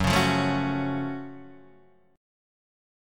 F#mM7 chord